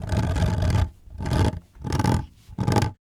Horror Sound
cartoon
Horror